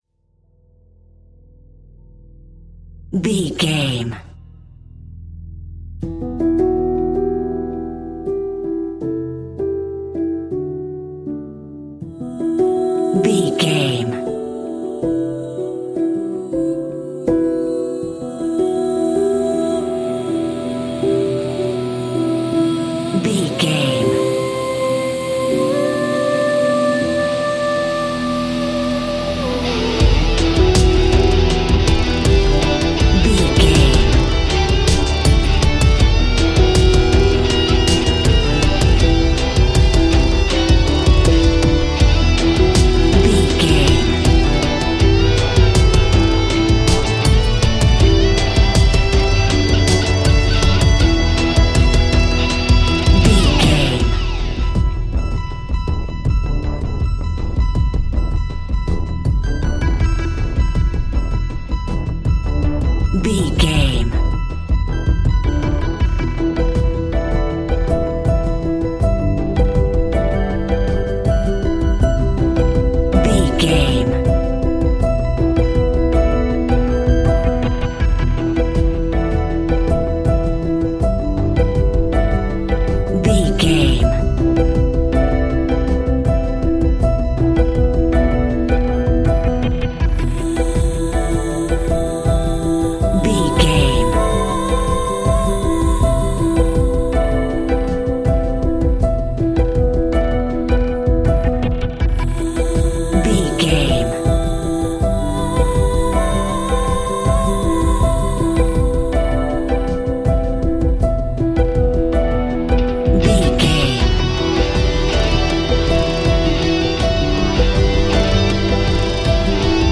Aeolian/Minor
dramatic
epic
powerful
strings
percussion
synthesiser
brass
violin
cello
double bass